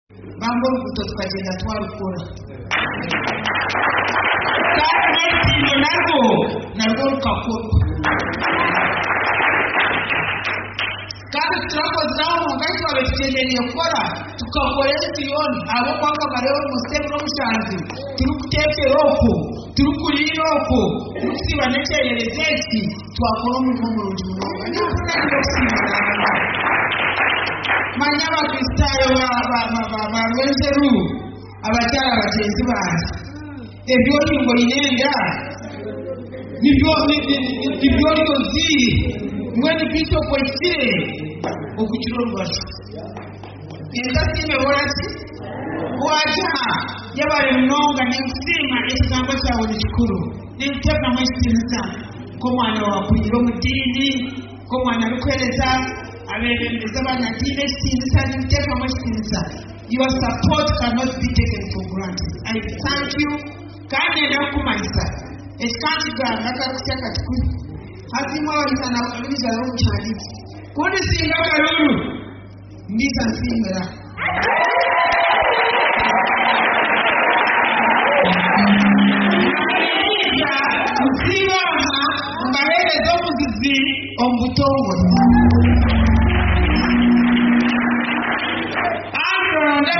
Speaking to congregants at Rwenjeru Pentecostal Ministries in Rwenjeru Sub-county on Sunday, Katusiime said the church and other faith-based organisations play a vital role not only in nurturing spiritual growth but also in promoting social and economic development.
Hon. Annet Katusiime Mugisha, Woman MP Bushenyi district addressing a congregation at Rwenjeru Pentecostal Church on Sunday 9th November, 2025.